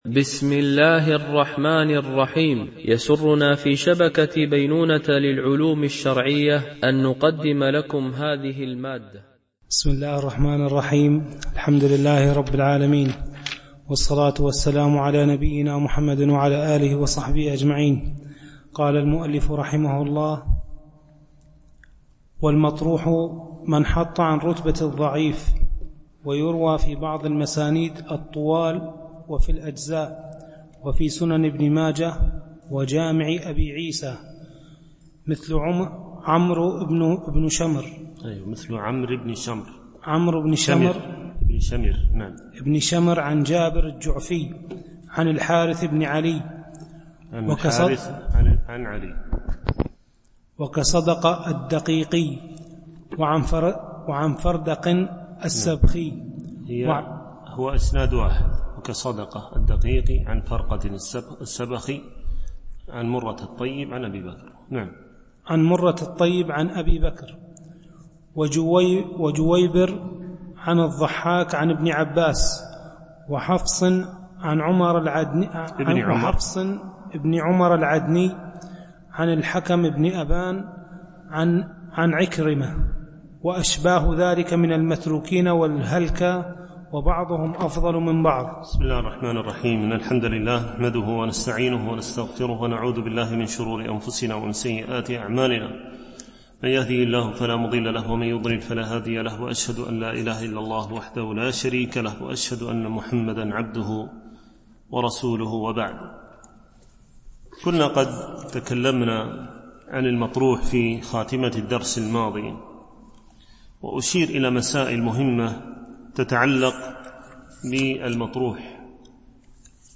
شرح الموقظة في علم مصطلح الحديث ـ الدرس 5 (الحديث المطروح والموضوع)